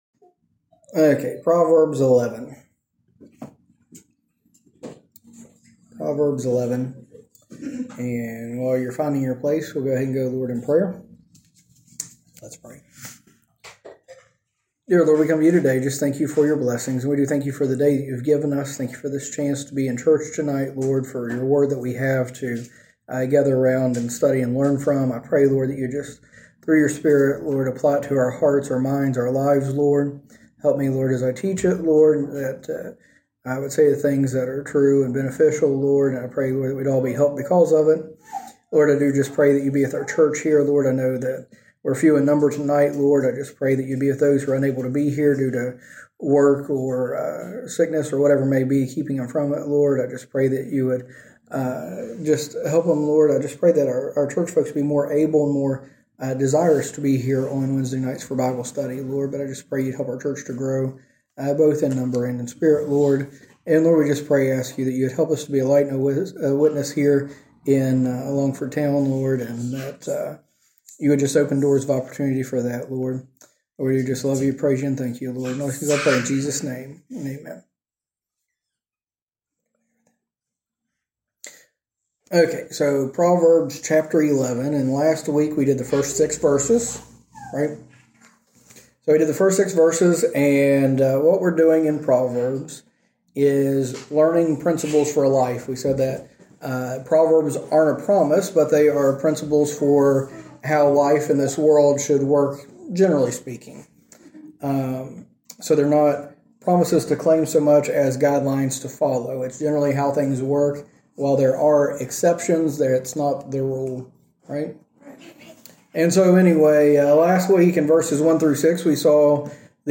A message from the series "Proverbs."